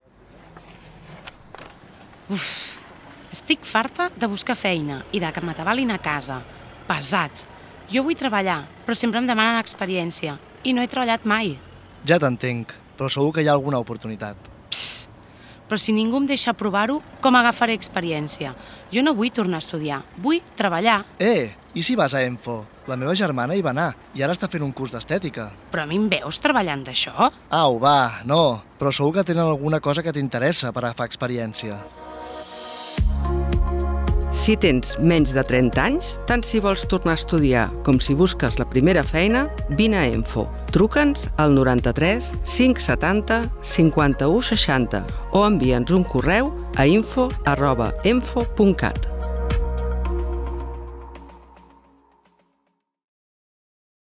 La setmana passada vam gravar dues noves falques de ràdio d’EMFO a Ràdio Mollet per fer difusió dels programes adreçats a joves i de la formació ocupacional per a persones en situació d’atur.